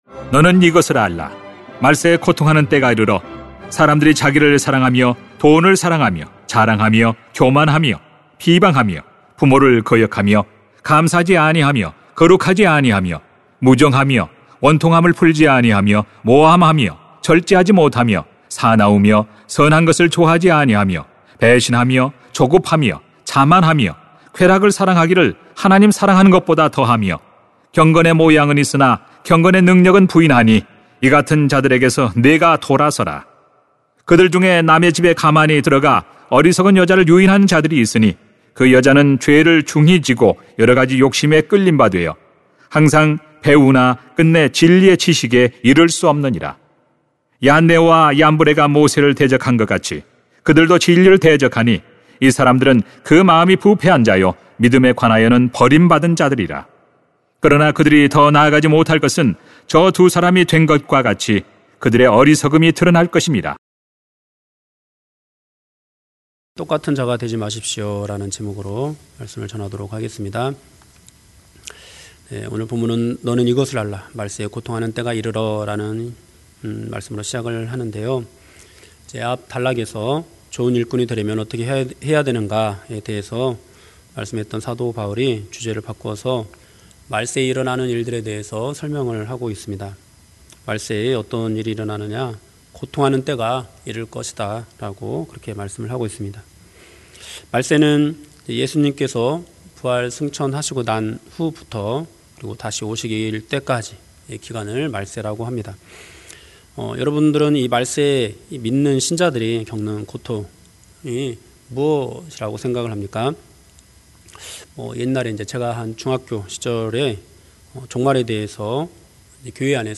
[딤후 3:1-9] 똑같은 자가 되지 마십시요 > 새벽기도회 | 전주제자교회